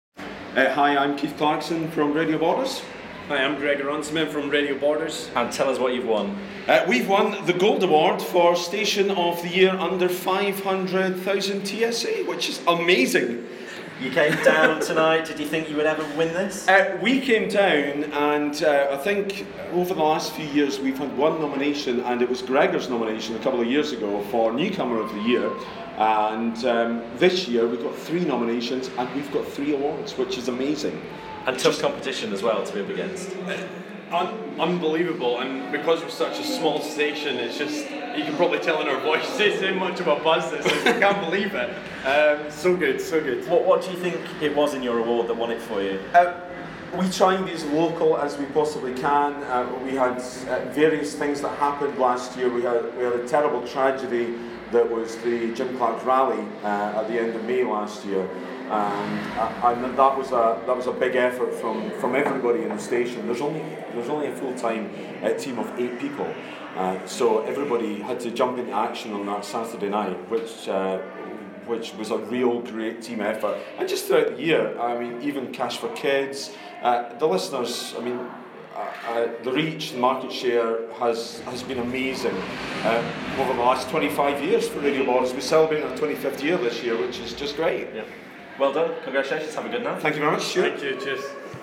An episode by RadioToday Live Interviews